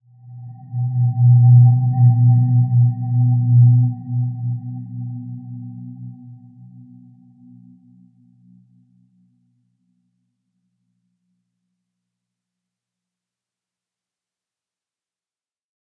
Dreamy-Fifths-B2-p.wav